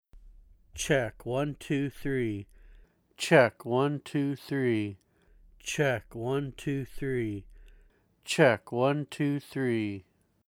I run the signal either through my Golden Age Pre73jr into the Steinberg UR44 audio interface, or go straight into the interface preamps without the Golden Age pre in line.
In each sample, I say "Check one two three" four times.
The third sample mp3 is comparing the F-20 using the Steinberg interface preamp vs the Golden Age preamp. The first "check one two three" is Steinberg pre, the second is the Golden Age pre, the third is back to the Steinberg pre, and finally the fourth is again the Golden Age pre.
Overall, I prefer the sound of the TLM102 over the F-20 (for male voice).
View attachment F20, Steinberg pre, Golden Age pre.mp3